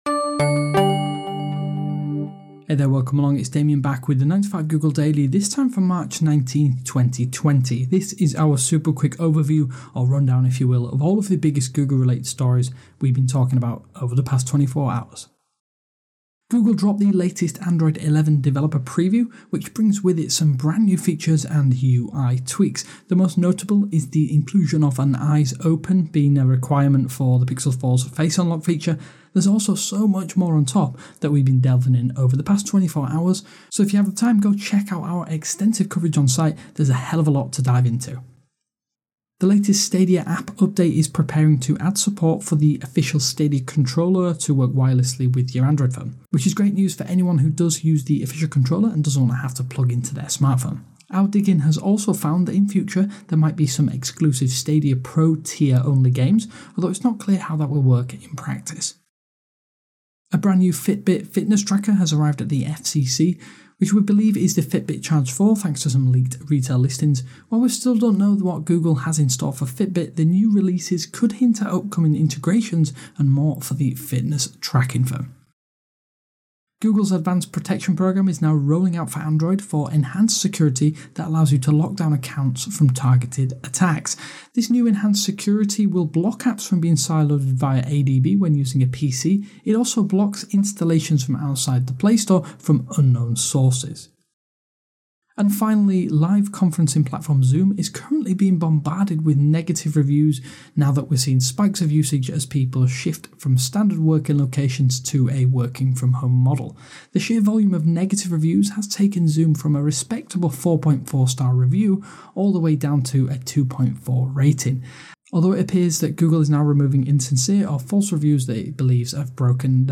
9to5Google Daily is a recap podcast bringing you…